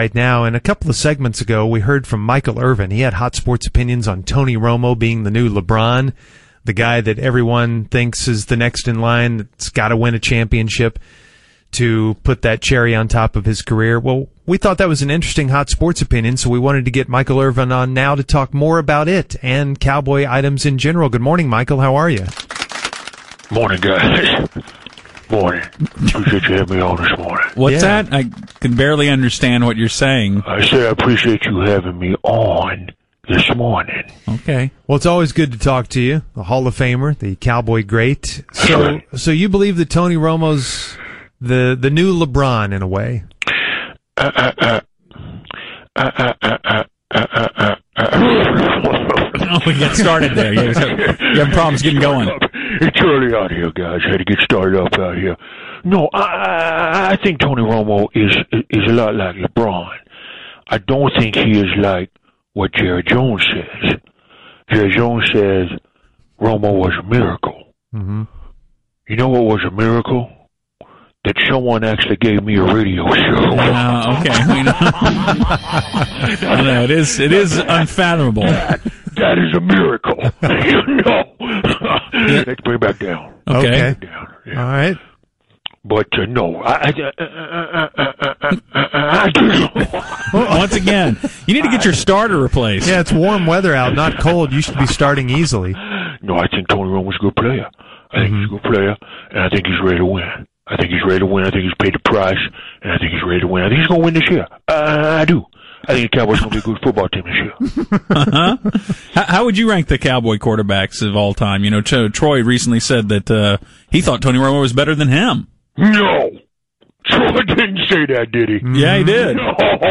Fake Michael Irvin stopped in to talk to the Musers about Tony Romo being the new Lebron.